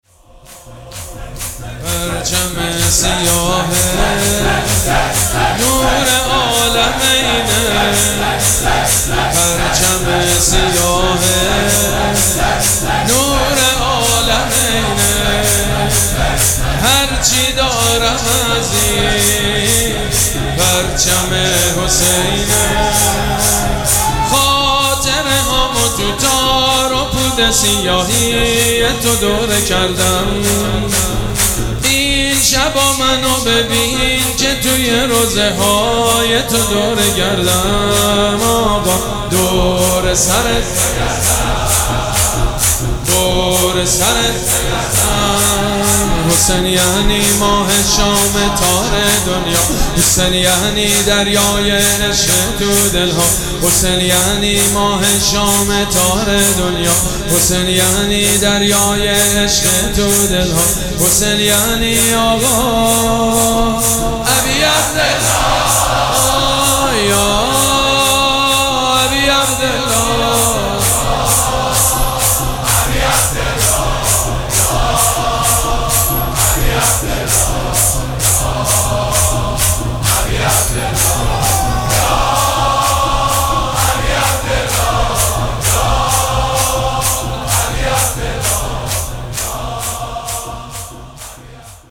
مراسم عزاداری شب چهارم محرم الحرام ۱۴۴۷
شور
مداح
حاج سید مجید بنی فاطمه